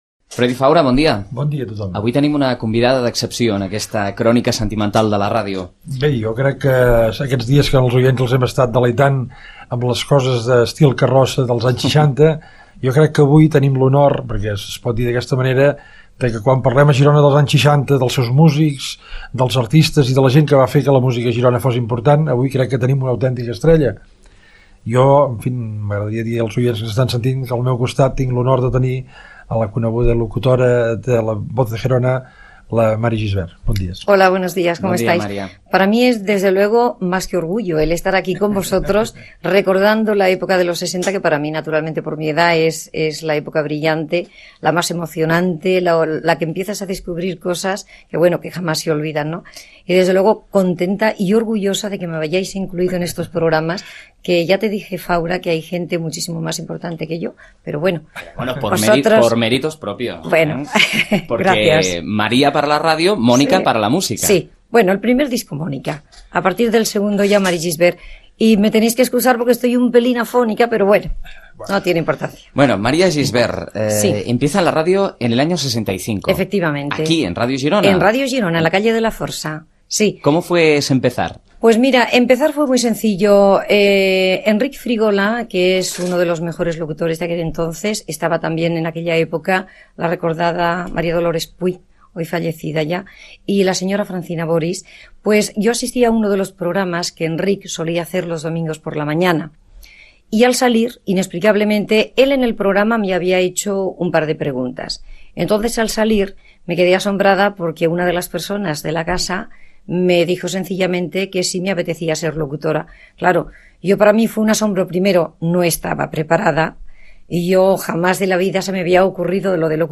Especial 65è aniversari de Ràdio Girona, 1998
Àudios: arxiu sonor de Ràdio Girona